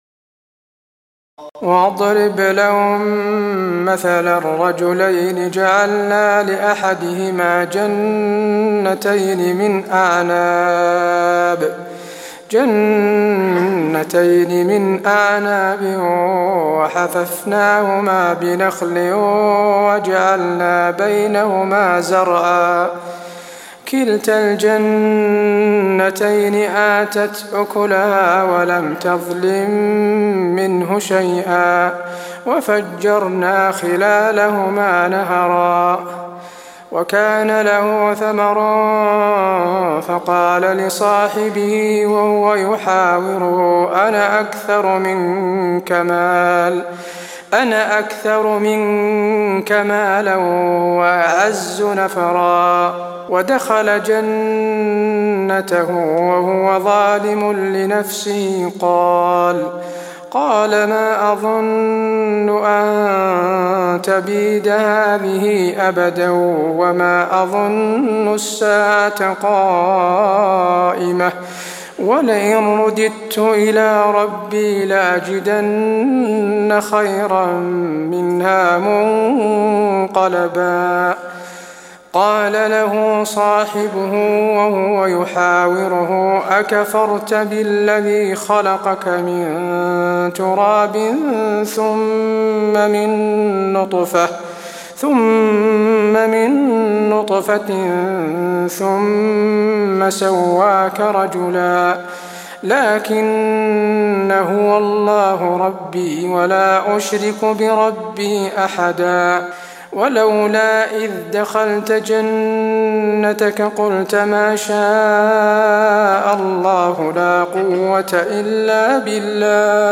تراويح الليلة الرابعة عشر رمضان 1423هـ من سورة الكهف (32-59) Taraweeh 14 st night Ramadan 1423H from Surah Al-Kahf > تراويح الحرم النبوي عام 1423 🕌 > التراويح - تلاوات الحرمين